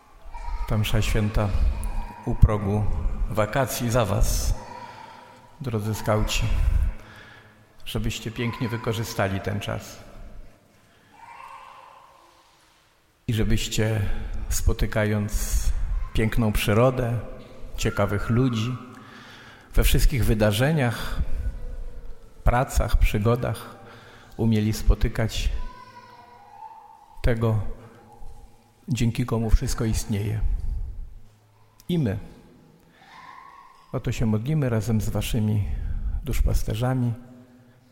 W sobotę, 26 czerwca, w Panteonie Wielkich Polaków została odprawiona Msza Święta, kończąca rok formacji Skautów Europy.
Na początku Eucharystii złożył młodym ludziom życzenia, aby podczas wakacji spotykali Chrystusa.
Biskup-Michal-Janocha-NA-poczatek-Eucharystii-dla-skautow.mp3